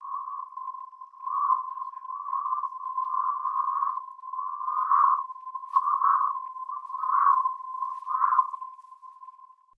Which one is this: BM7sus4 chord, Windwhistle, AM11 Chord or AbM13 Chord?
Windwhistle